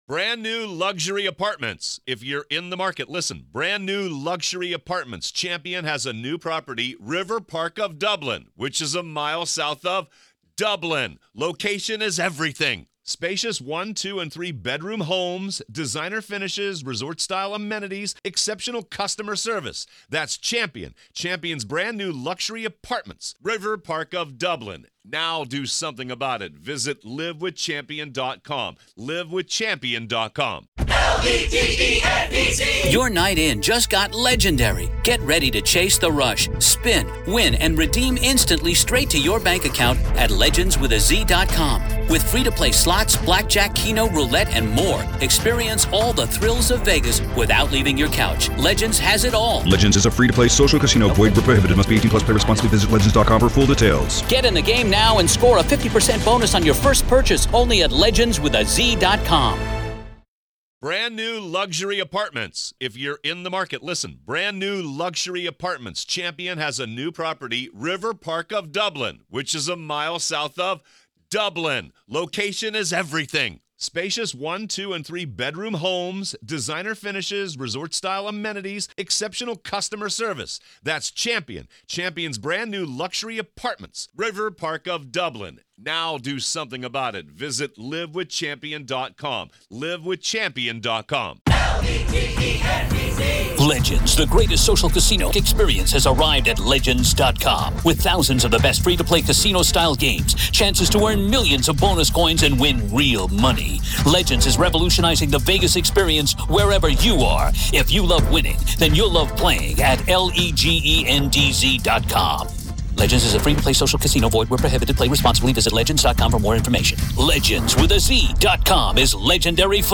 True Crime Today | Daily True Crime News & Interviews / Devils Den EXCLUSIVE